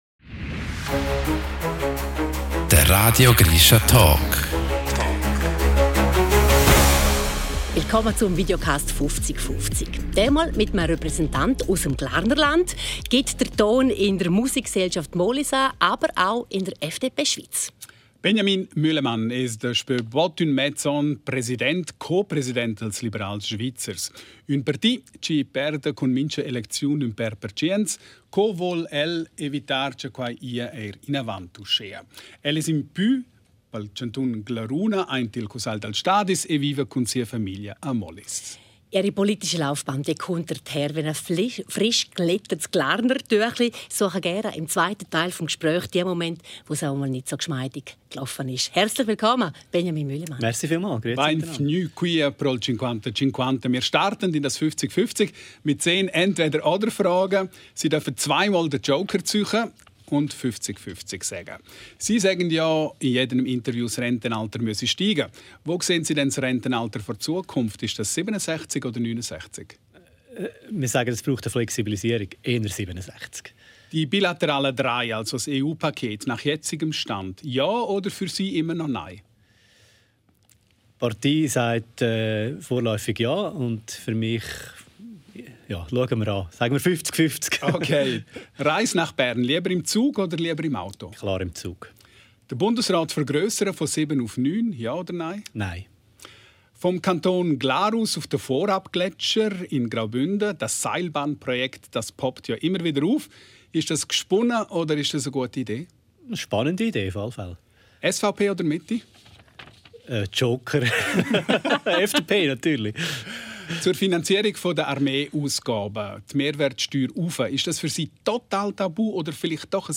Im Gespräch zeigt er, warum er trotz Gegenwind an Sparpolitik glaubt – und wie viel Kompromiss ein Doppelpräsidium wirklich verträgt.